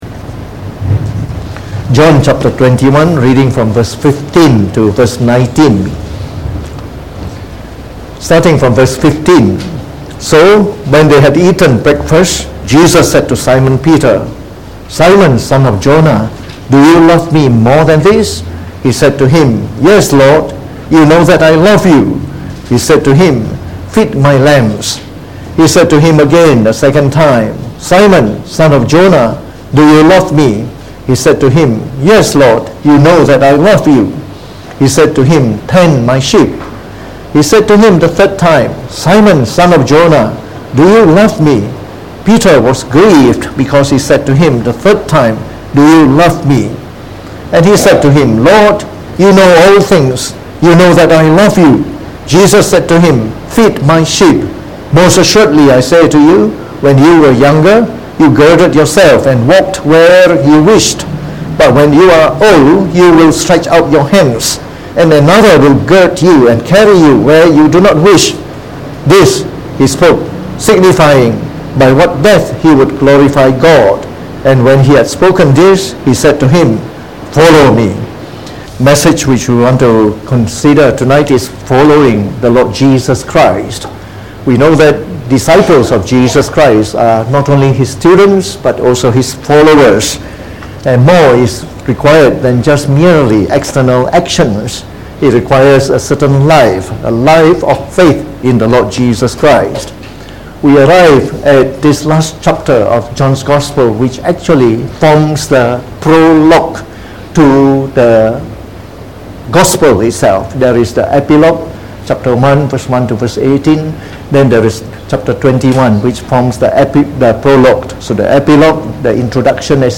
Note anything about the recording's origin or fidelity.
From our series on the Gospel of John delivered in the Evening Service